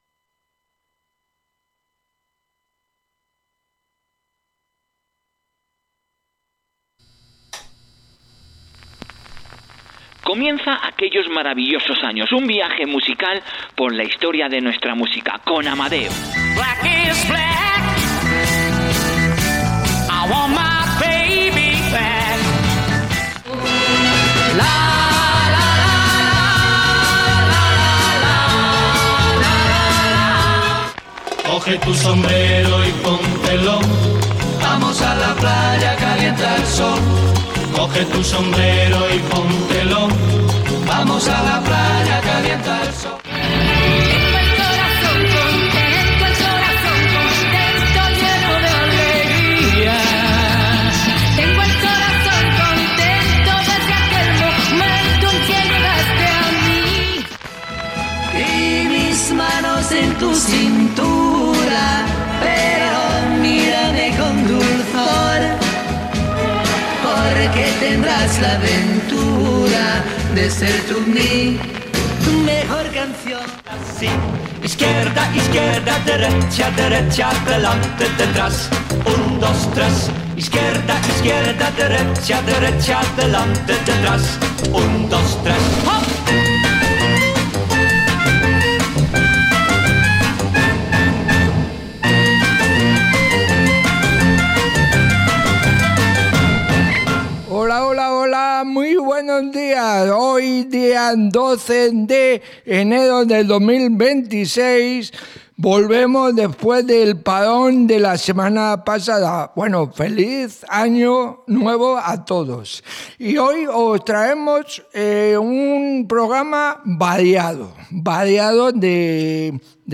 Radio The Sentinel te trae un programa cargado de buen rock